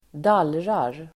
Ladda ner uttalet
Uttal: [?d'al:rar]